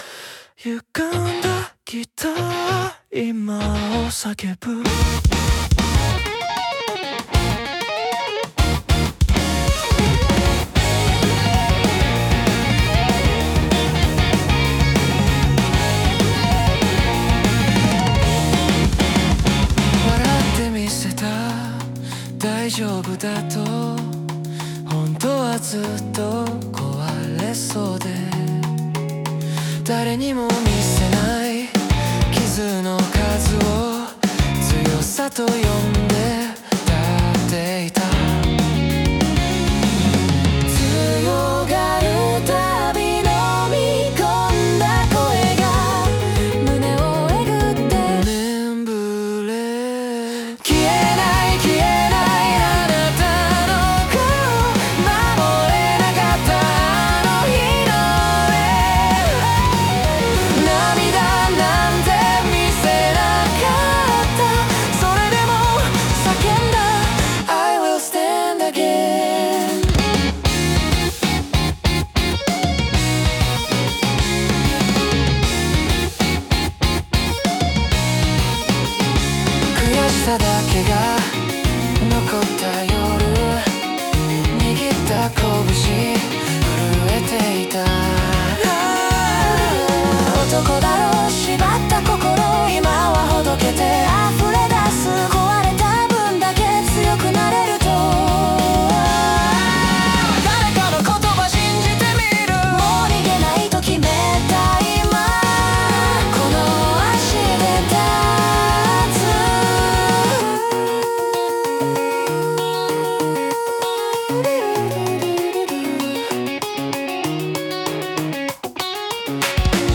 イメージ：邦ロック,男性ボーカル,かっこいい,切ない